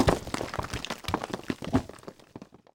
runAground.wav